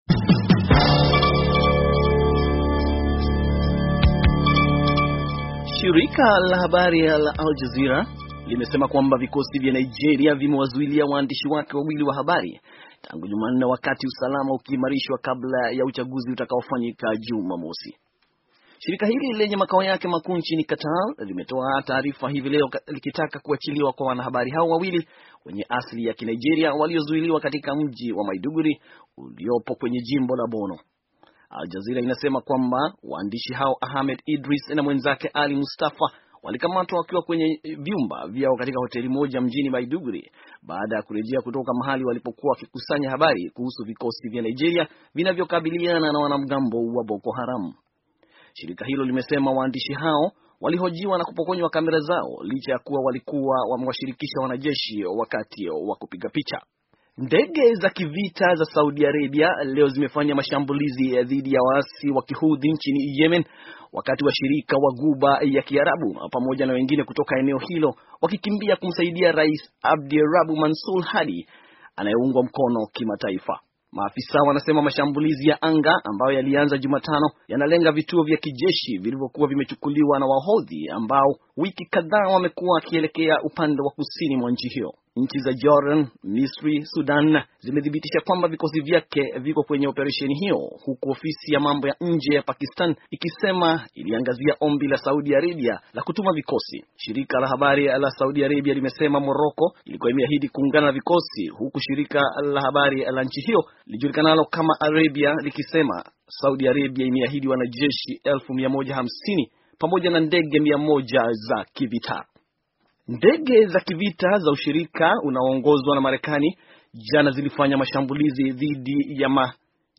Taarifa ya habari - 6:34